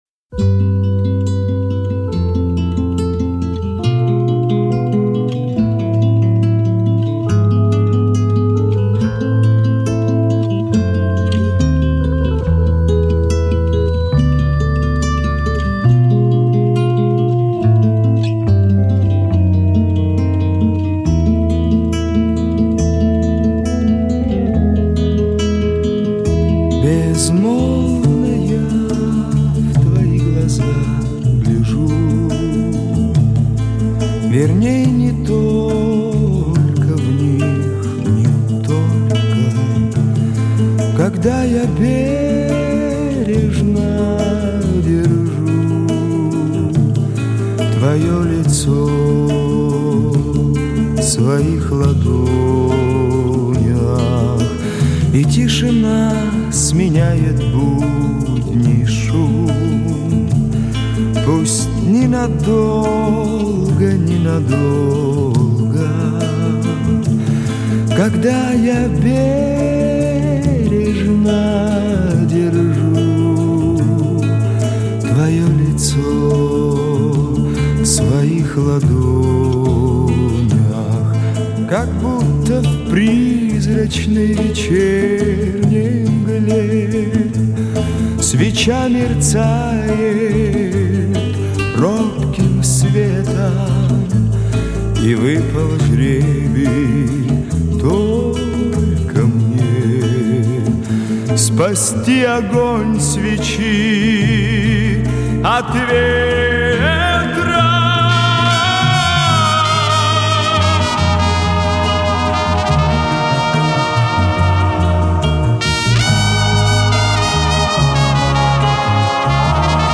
Битрейт ниже, а качество лучше